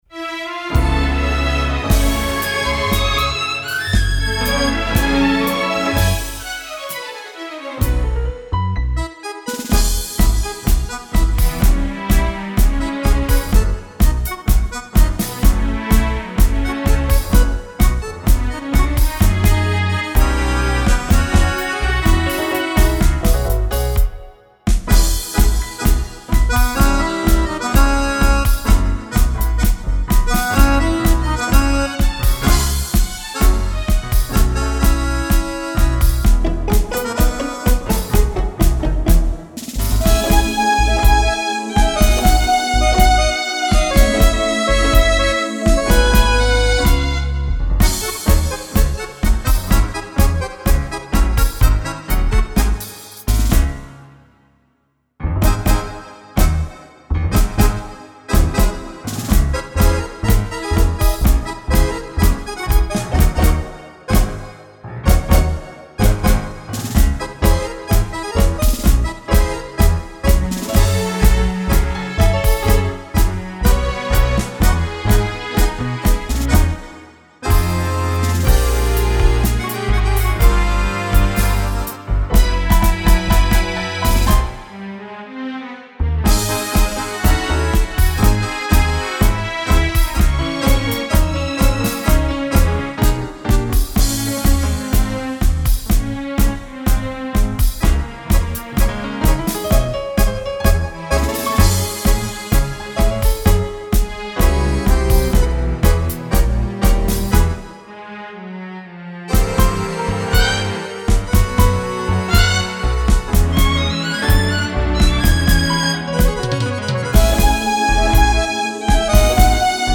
(solo base)